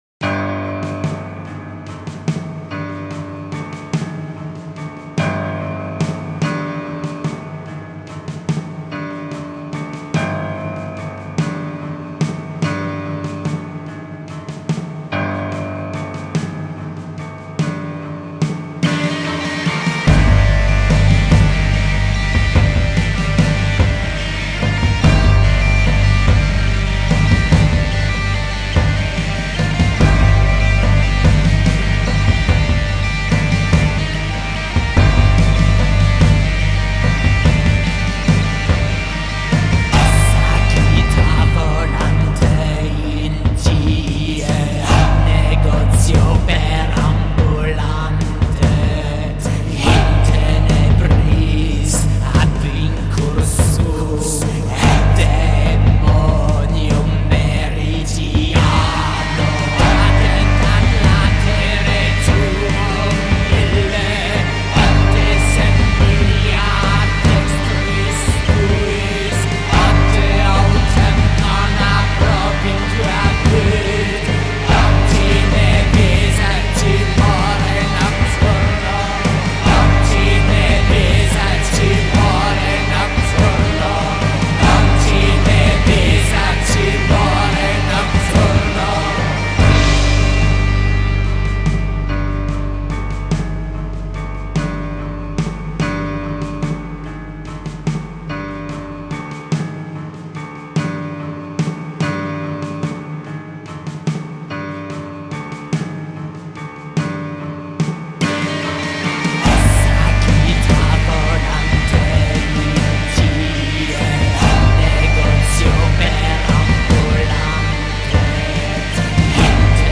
Sombres, incantatoires et mécaniques